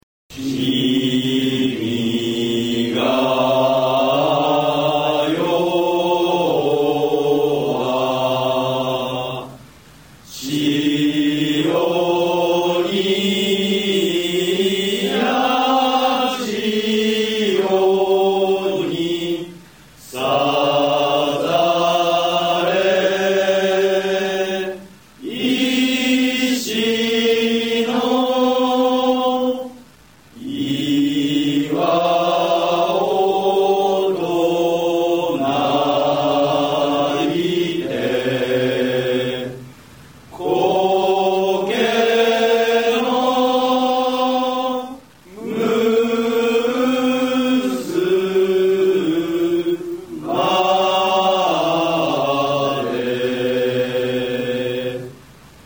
Wersja a cappella
hymn_japonii.mp3